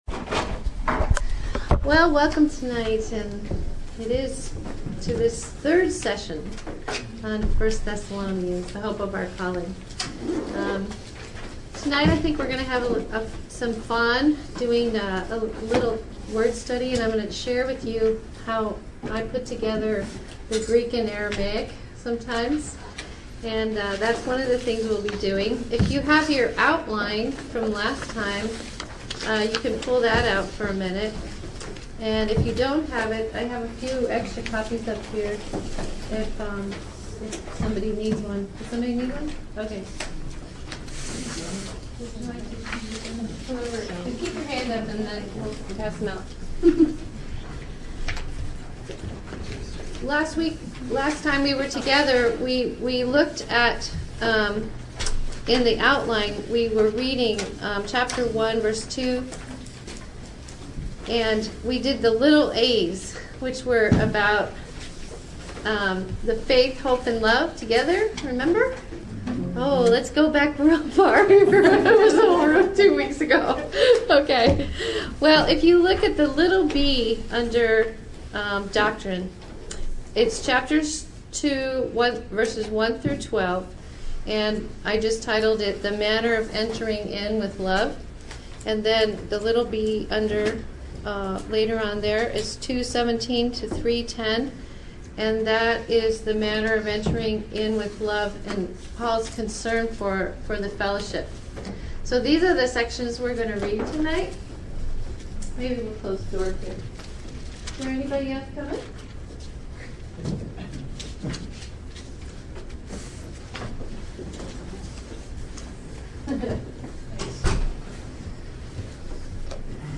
Dive into Part 3 of the Thessalonians Audio Teaching Series and witness the powerful, lasting results of preaching the gospel in Thessalonica.